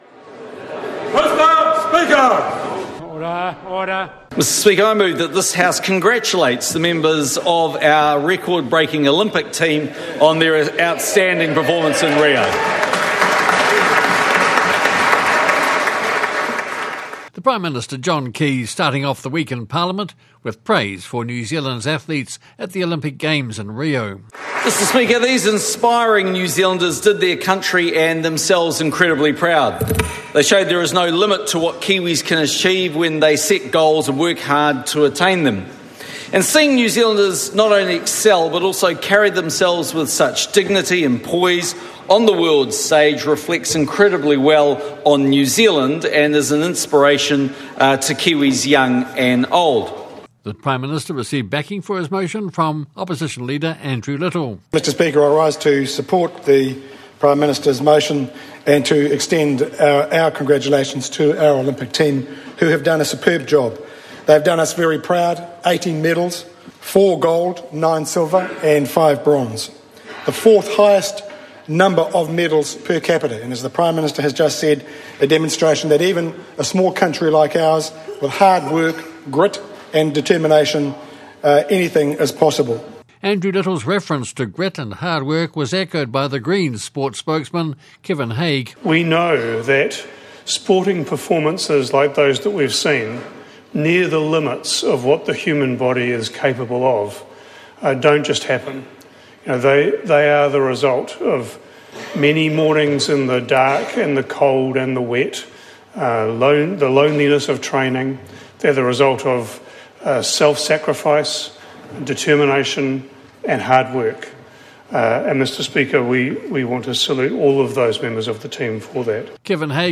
Week in the House begins with a round of speeches praising the performance of New Zealand’s athletes at the Olympic Games in Rio.